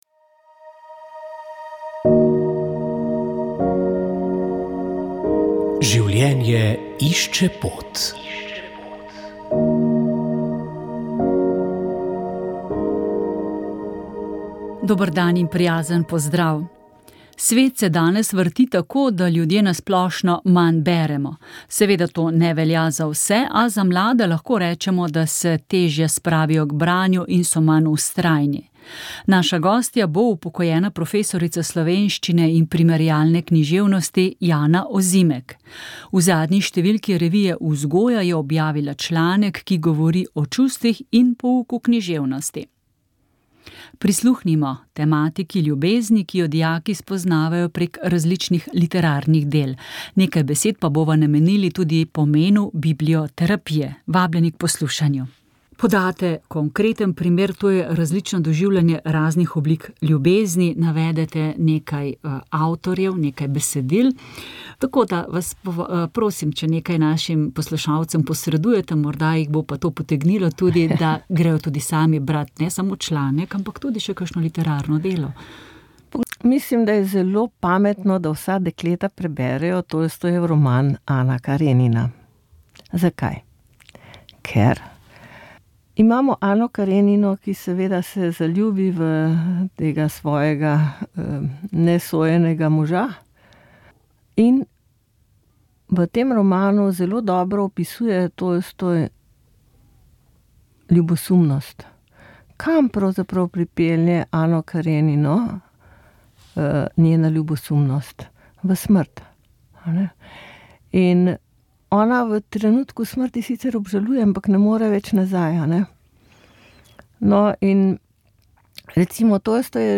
Sveta maša
Sv. maša iz stolnice sv. Janeza Krstnika v Mariboru